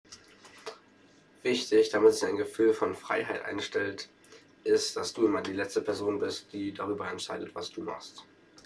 Ein Fest für die Demokratie @ Bundeskanzleramt, Berlin